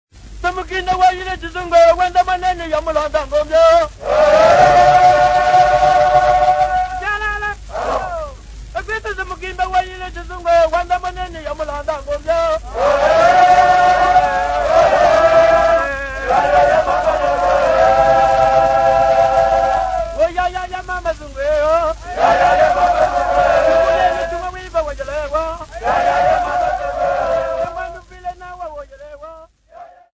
Chokwe men and women
Popular music--Africa
Field recordings
Indigenous music